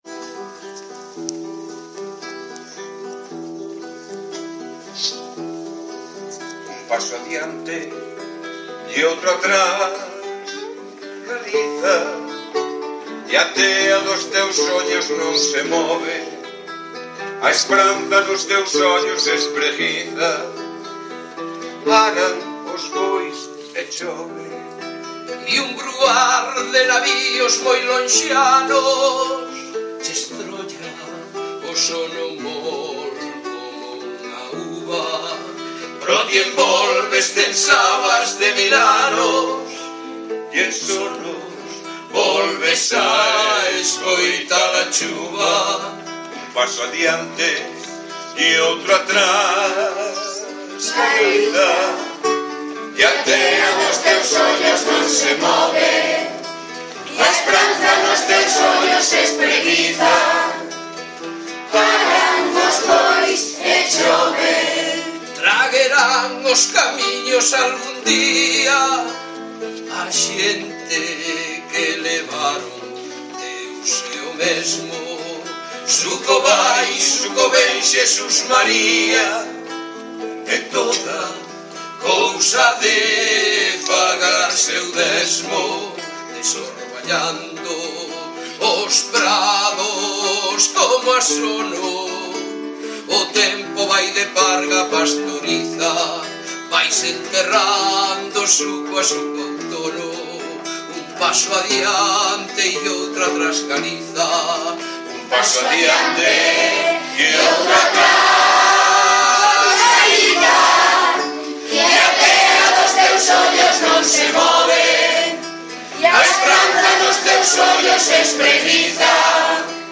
Ceip de Arzúa con Mini e Mero